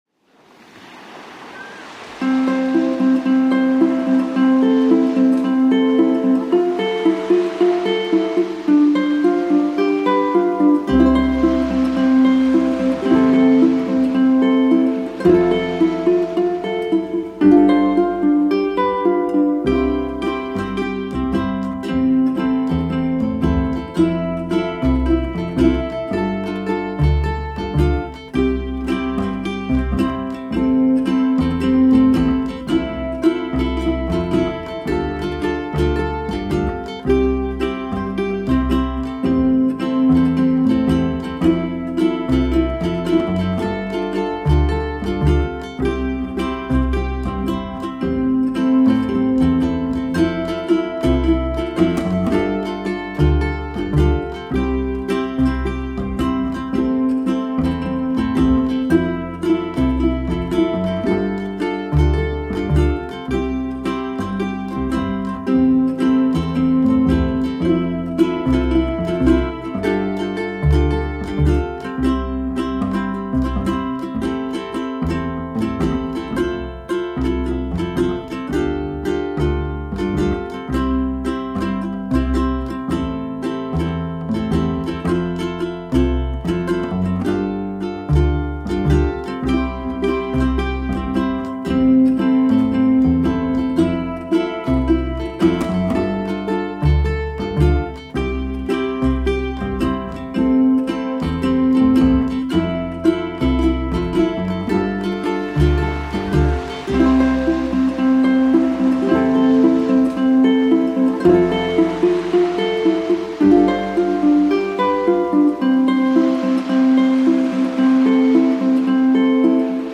Amb-el-cor-ben-content-INSTRUMENTAL.mp3